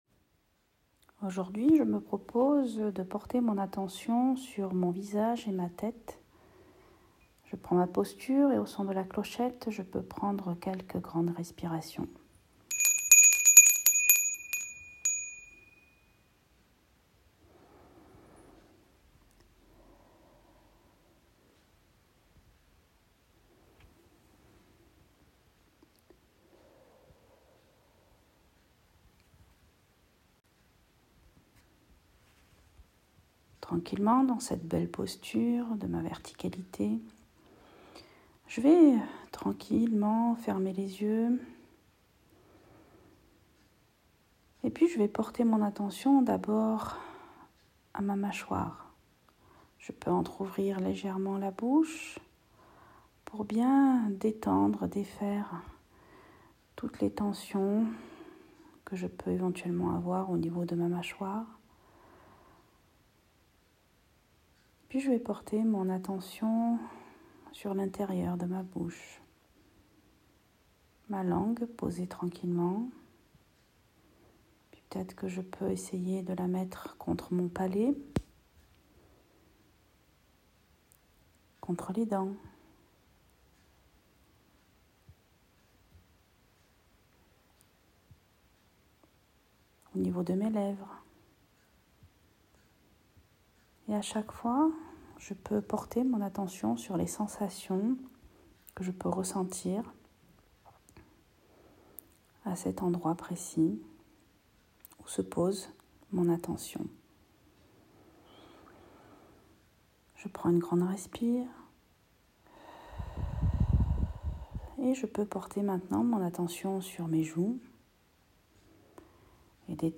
Meditation-tete.m4a